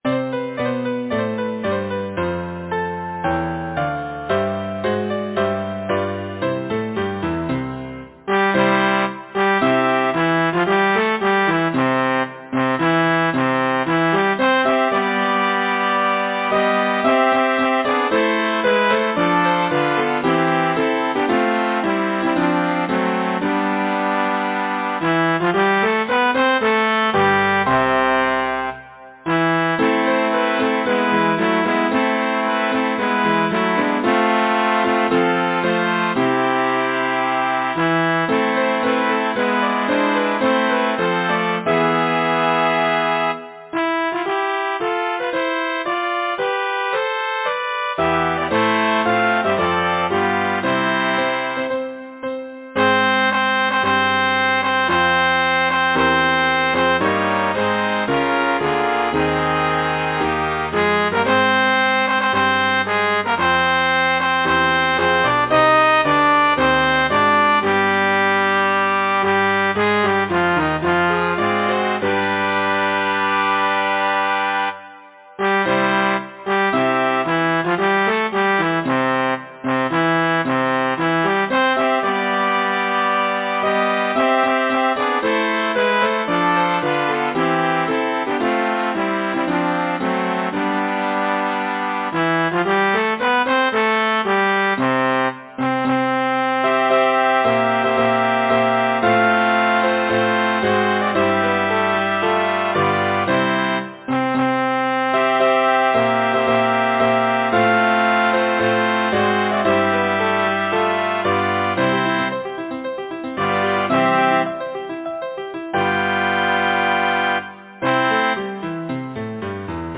Language: English Instruments: Keyboard
First published: 1881 J. M. Russell Description: Keyboard accompaniment, one section indicates organ.